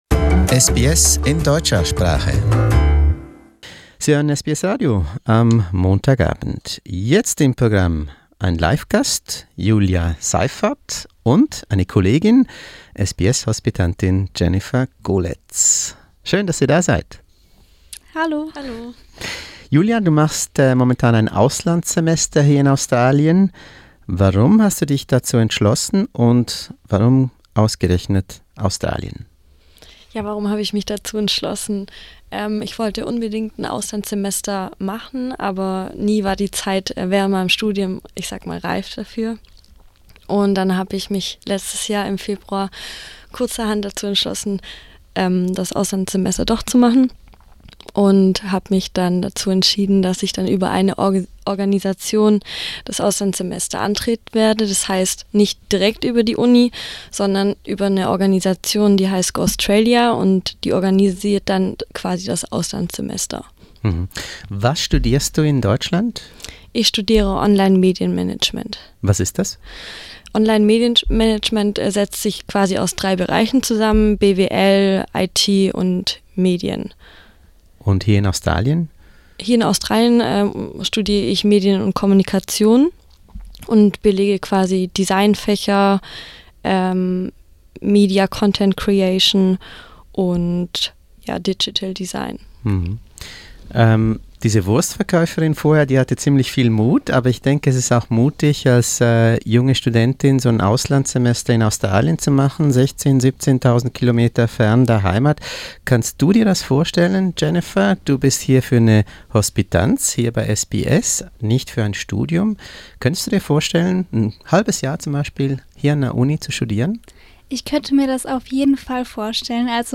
What is it about our universities that leads these students to pack their bags and board a 24-hour-flight? Listen to a studio talk to find out more.